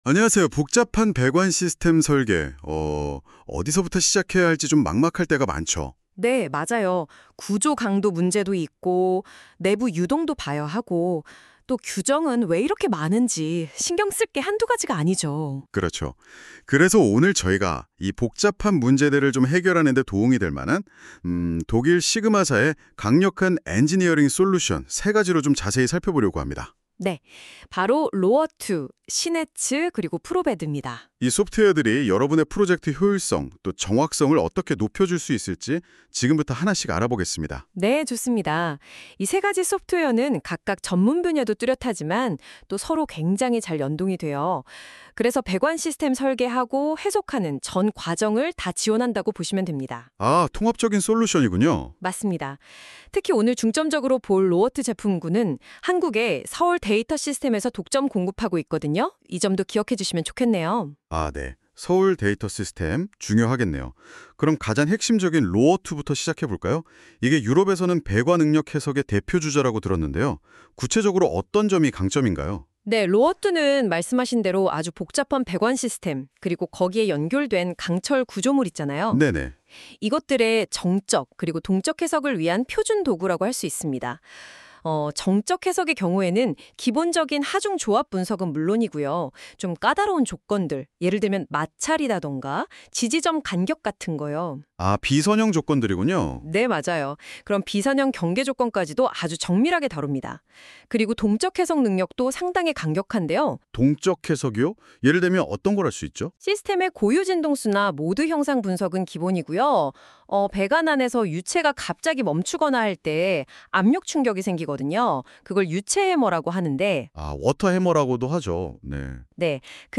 SIGMA의 소프트웨어를 AI 기반의 팟캐스트 형식으로 들어보세요.
각 제품의 핵심 개념과 차별점까지, 인공지능의 목소리로 안내해드립니다.